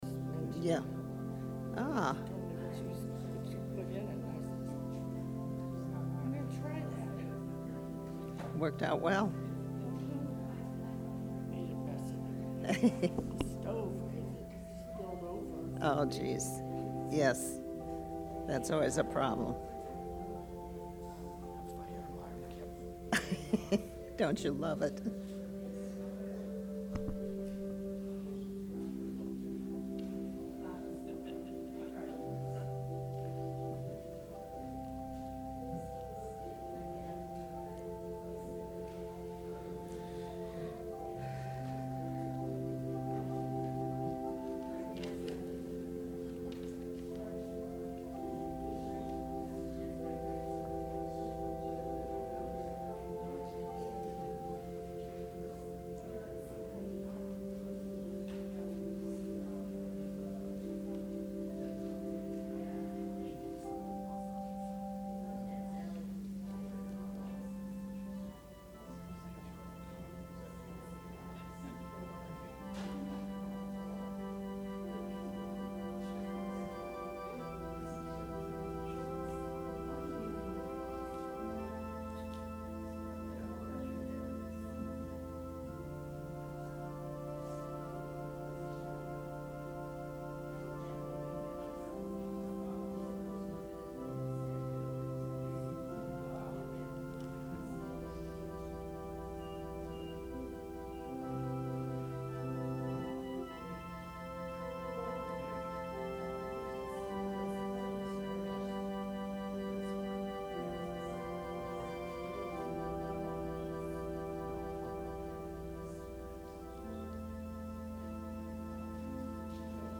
Sermon – December 1, 2019
advent-sermon-december-1-2019.mp3